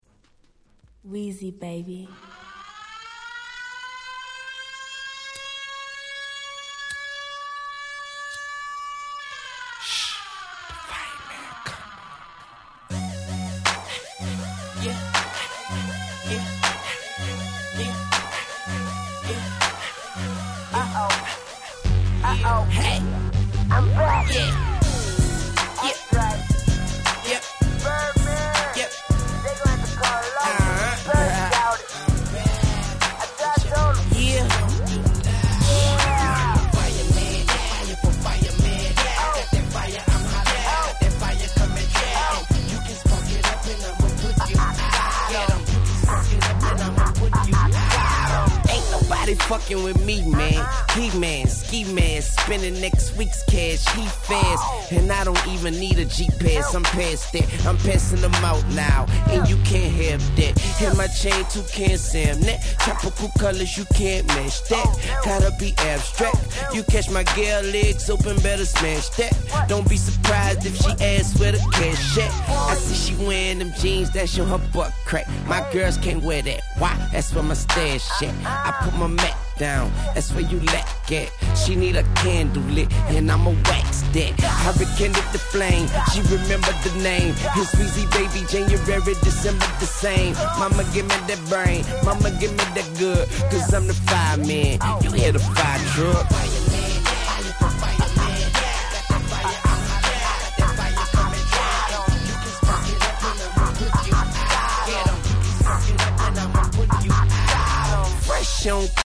05' Super Hit Hip Hop LP !!
Dirty South寄りの曲が目立った前作から打って変わって本作はSoulfulでMellowな格好良い曲が満載。